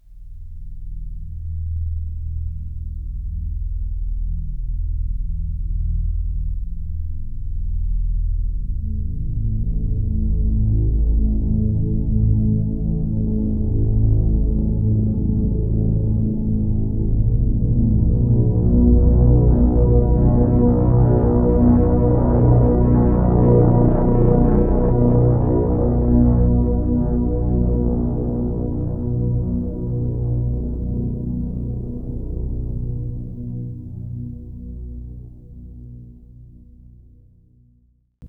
Synthesizermusik
All you hear is a single Waldorf WAVE+16